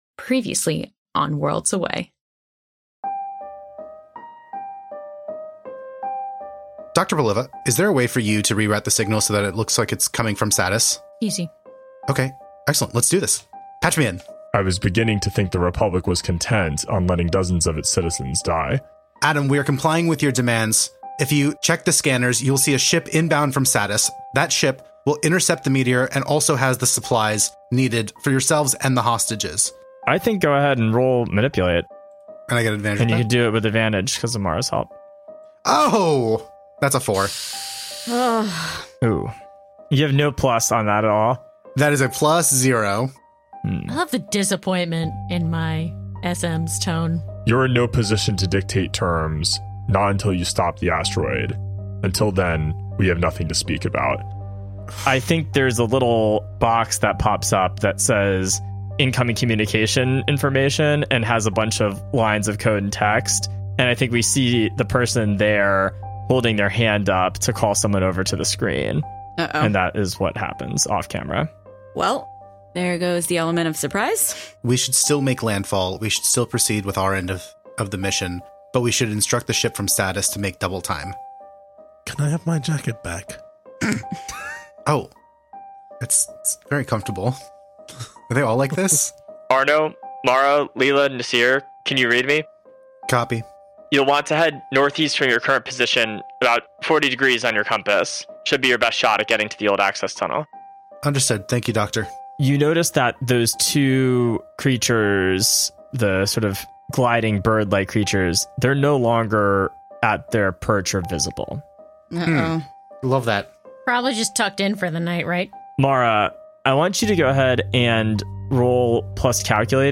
Worlds Away is an actual-play storytelling podcast where five close friends use games to create adventures together as a collective.